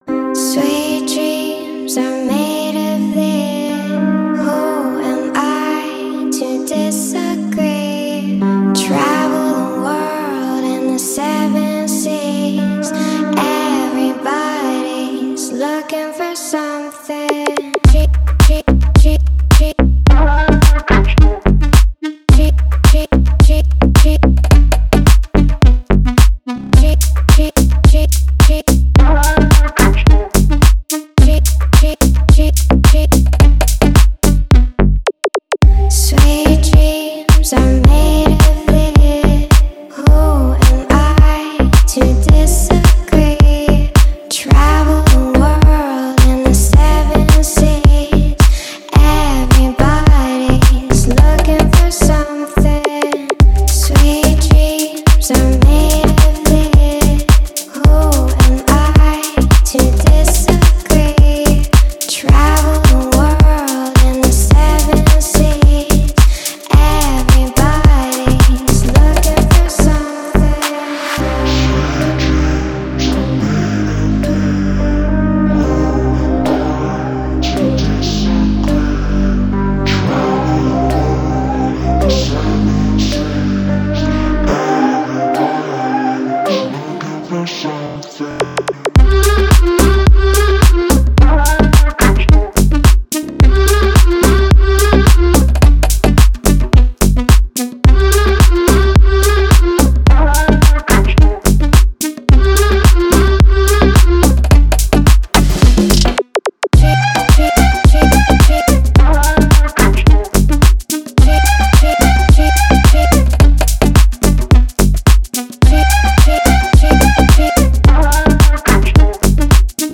ремиксы